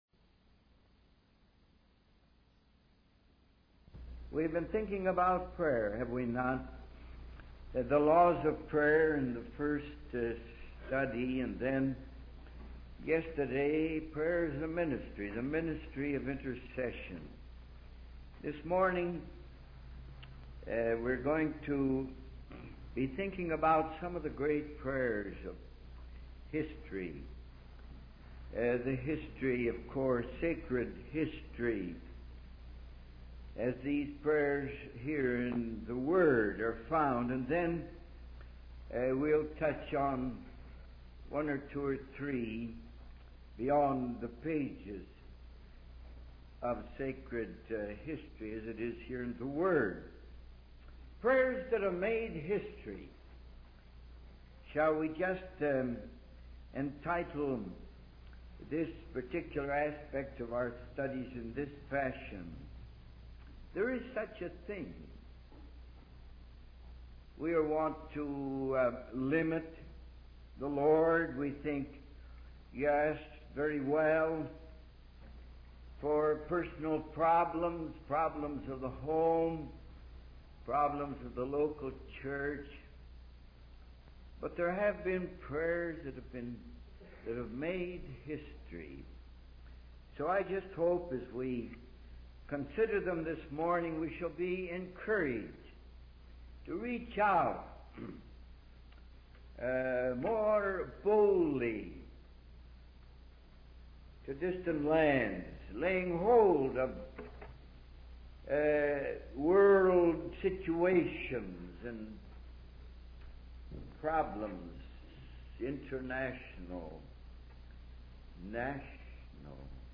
In this sermon, the preacher discusses the story of Daniel in the lion's den from the Bible. He highlights how Daniel remained faithful to God and continued to pray despite a decree that forbade it.